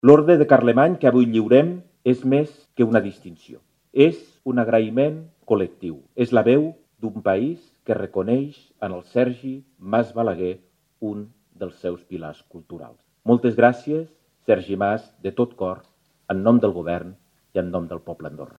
L’acte de lliurament, presidit pel cap de Govern, Xavier Espot, s’ha celebrat al Centre Cultural Lauredià.
En el seu discurs, el cap de Govern ha lloat la seva obra i ha afegit que cada creació de l’artista “batega amb una Andorra que ja no hi és del tot, però que continua viva gràcies a persones com ell”.